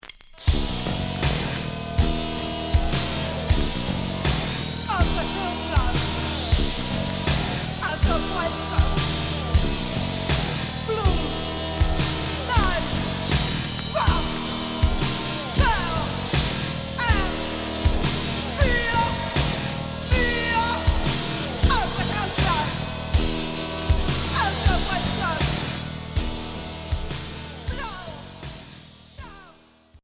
Noise core